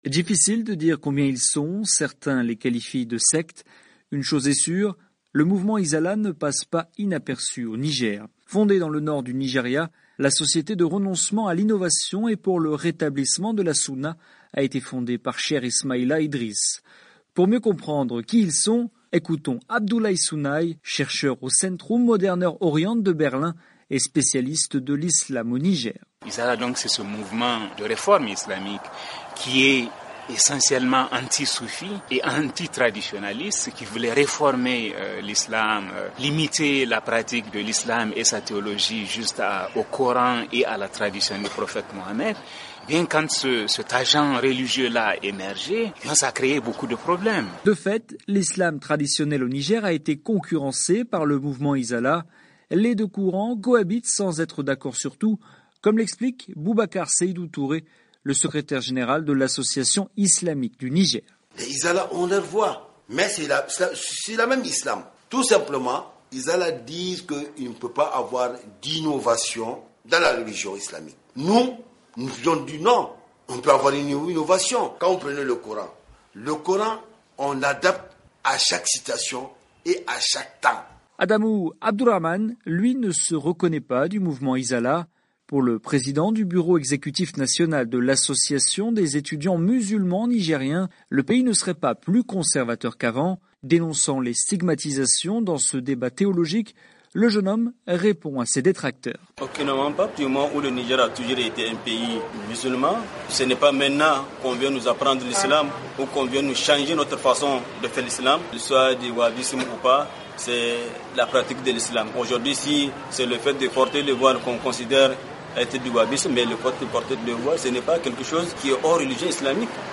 Reportage sur le mouvement Izala du Niger